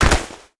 Foley Sports / Football - Rugby / Pads Hit Powerful Tackle.wav
Pads Hit Powerful Tackle.wav